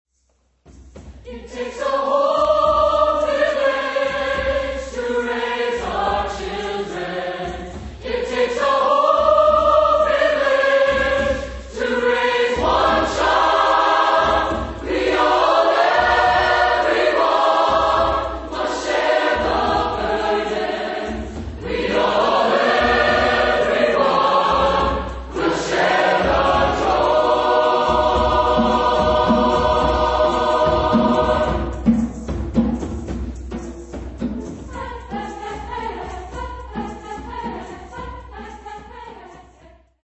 Type de choeur : SATB  (4 voix mixtes )